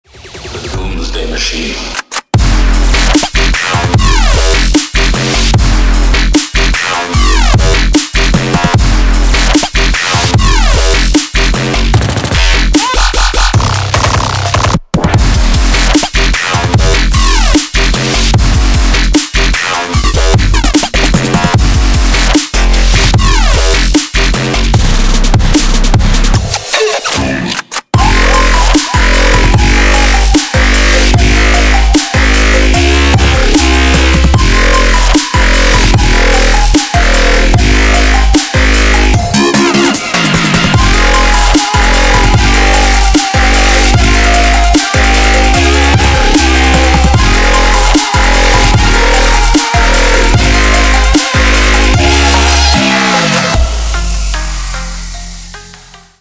Bass House